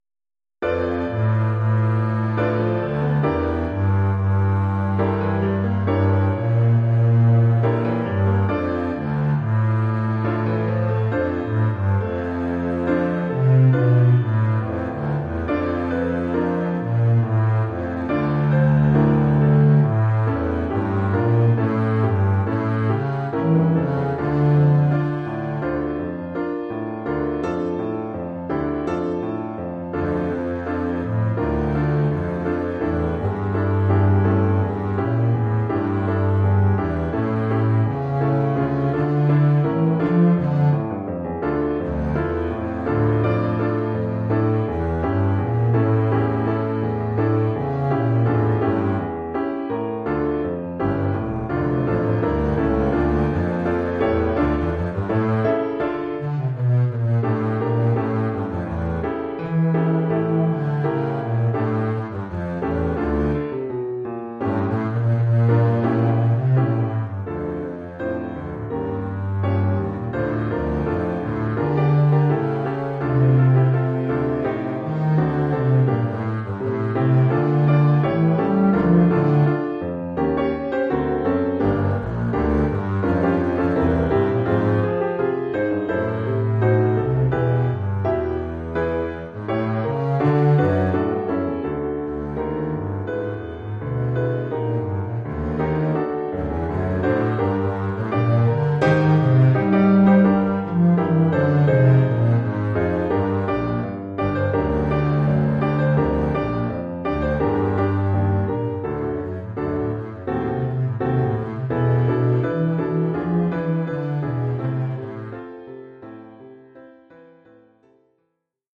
Oeuvre pour contrebasse et piano.